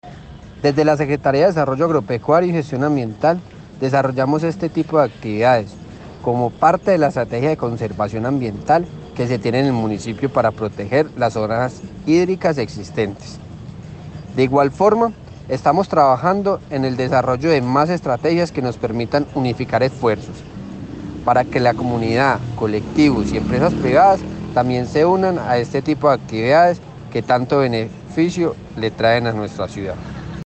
Escuchar Audio: Secretario de Desarrollo Agropecuario y Gestión Ambiental, Mateo Montoya.